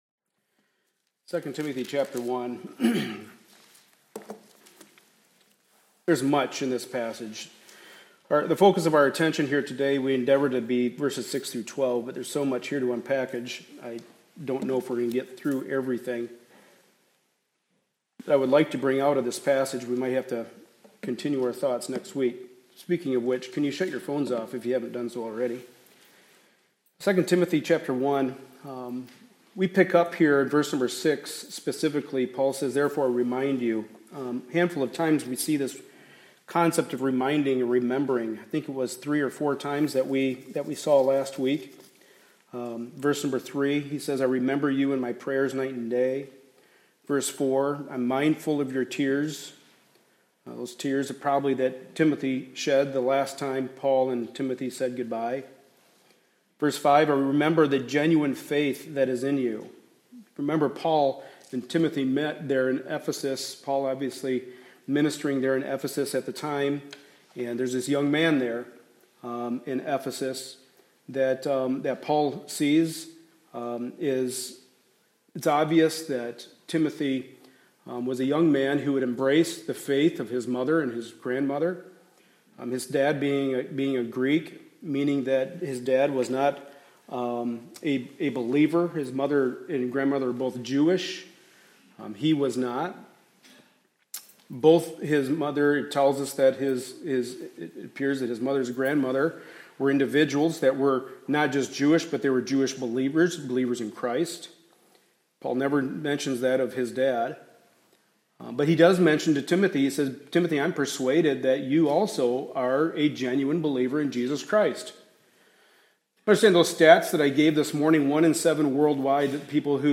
2 Timothy 1:6-12 Service Type: Sunday Morning Service A study in the Pastoral Epistles.